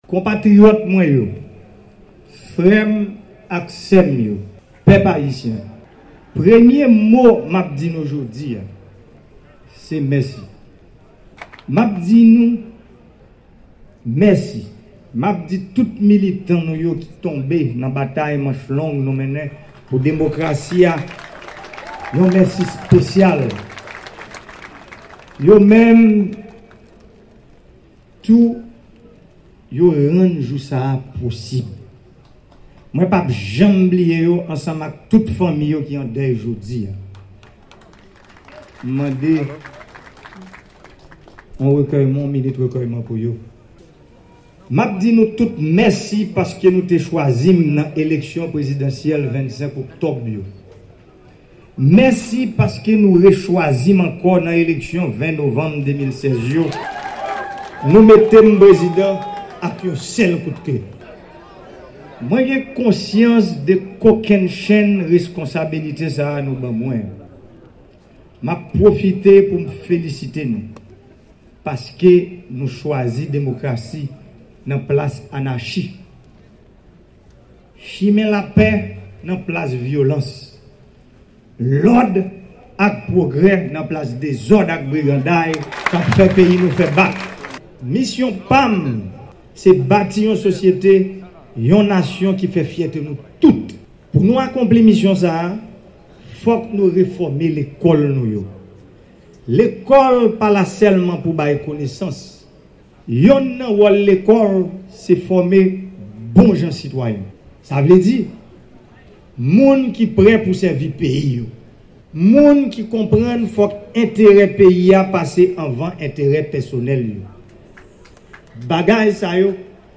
Yon pati nan dIskou M. Jovenel Moise fè nan okazyon prestasyon sèman li kòm 58èm Prezidan d Ayiti.